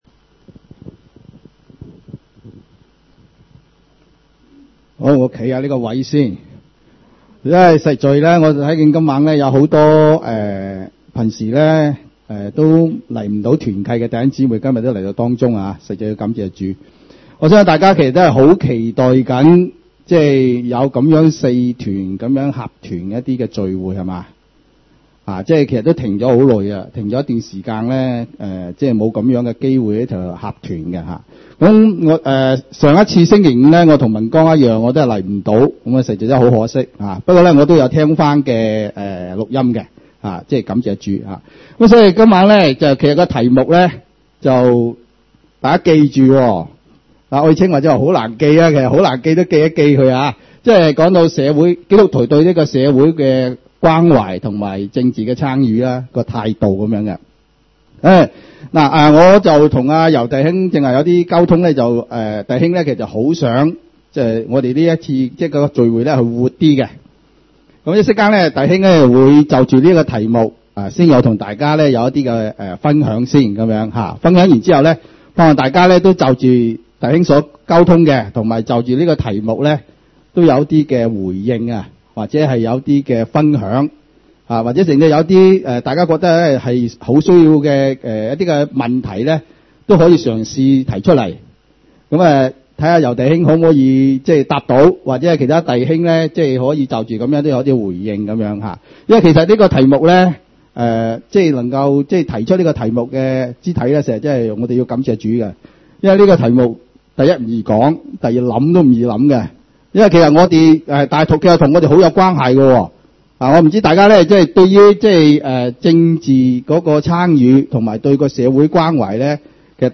荃灣基督徒聚會所